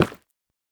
Minecraft Version Minecraft Version latest Latest Release | Latest Snapshot latest / assets / minecraft / sounds / block / nether_ore / break3.ogg Compare With Compare With Latest Release | Latest Snapshot